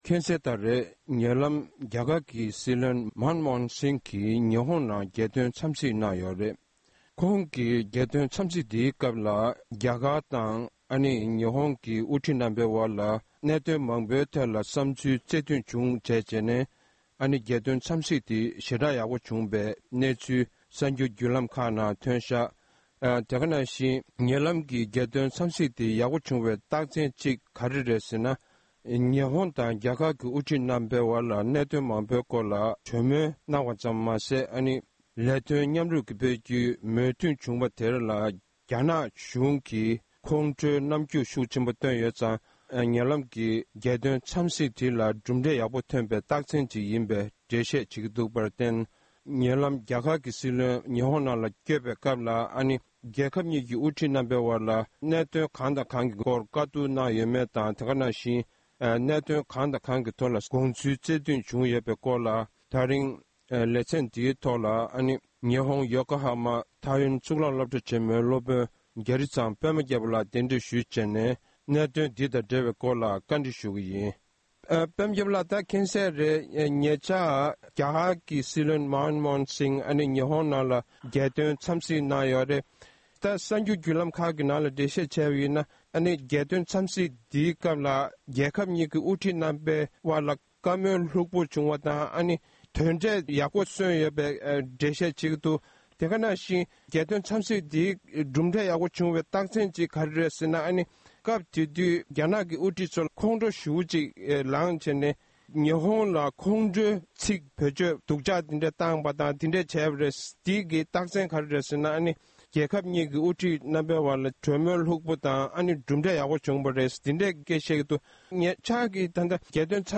གནས་འདྲི